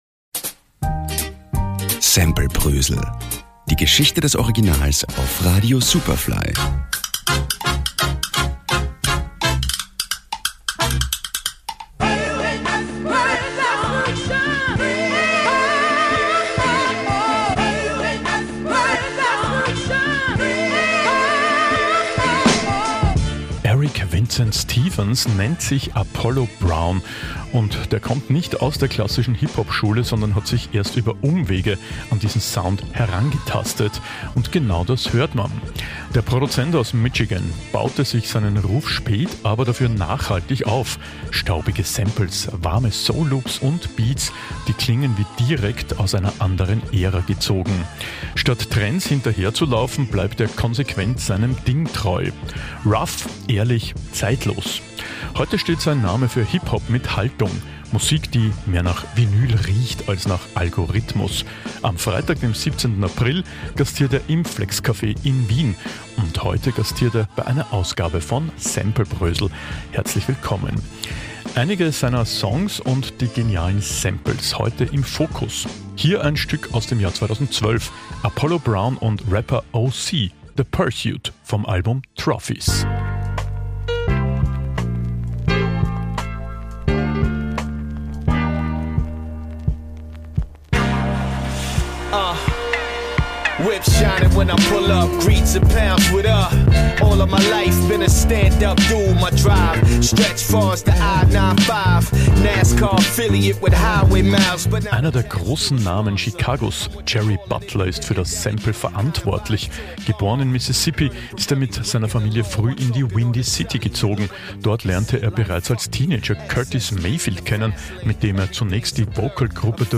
Der Produzent aus Michigan baut sich seinen Ruf spät, aber dafür nachhaltig auf: staubige Samples, warme Soul-Loops und Beats, die klingen wie direkt aus einer anderen Ära gezogen.